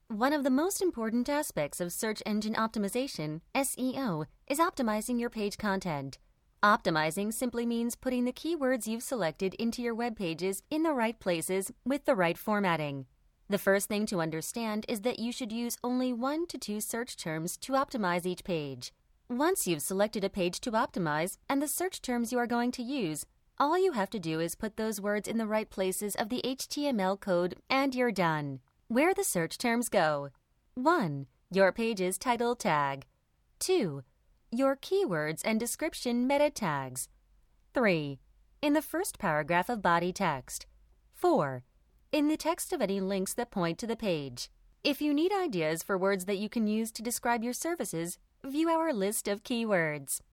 Female
My vocal range extends from a 20's bright and bubbly to a 40's warmly confident.
E-Learning